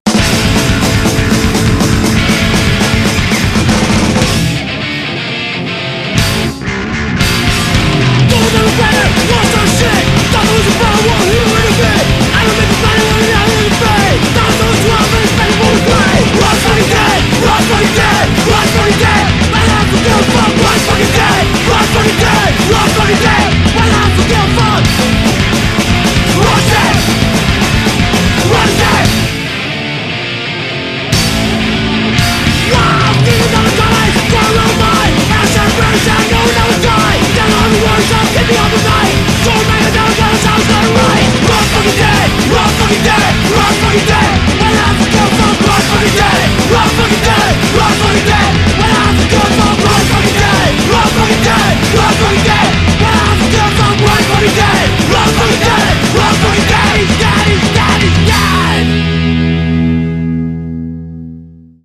Punkhardcore da Roma